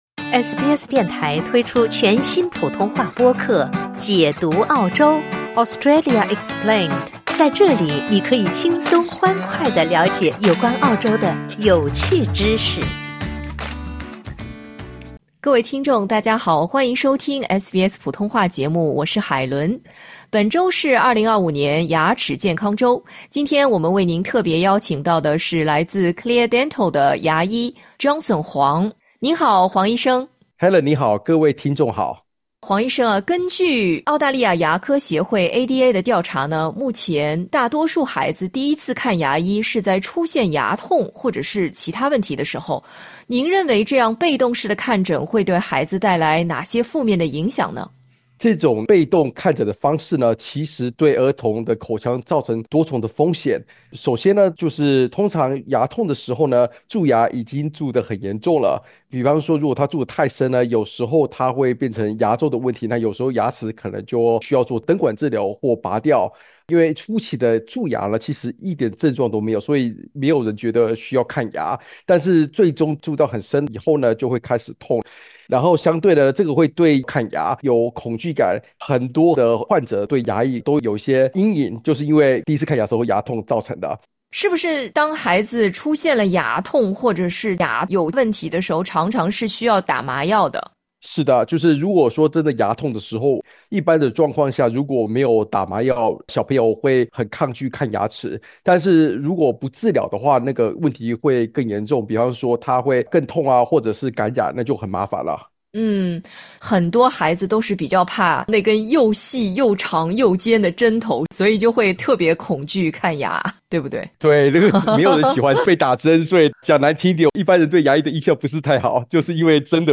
在接受SBS普通话节目采访时表示